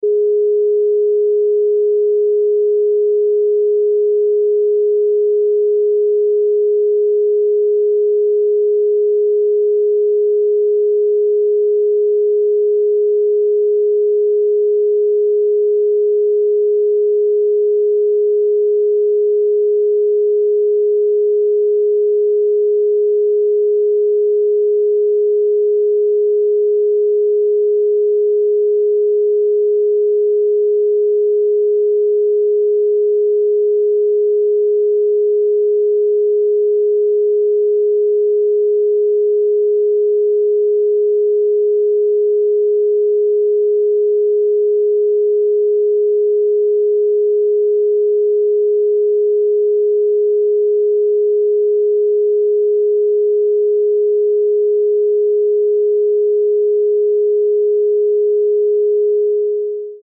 417 HZ Solfeggio Frequency Pure sound effects free download
417 HZ Solfeggio Frequency Pure Tone